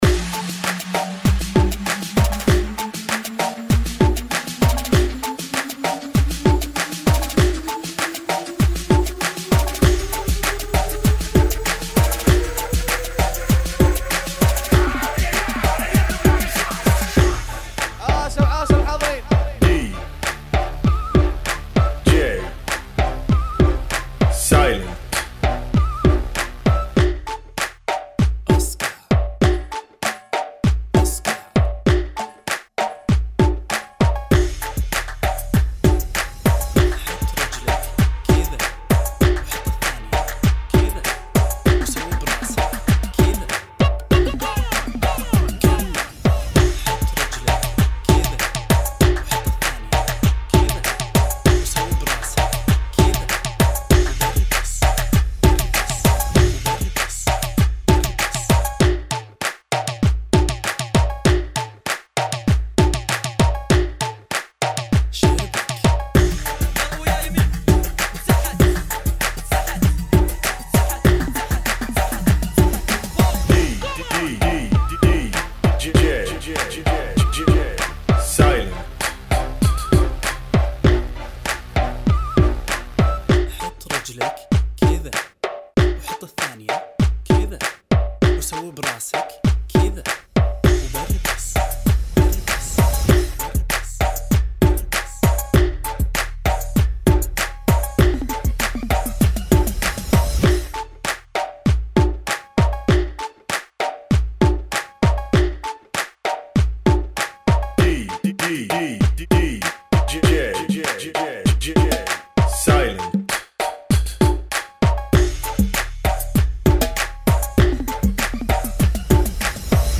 [ 114 BPM ]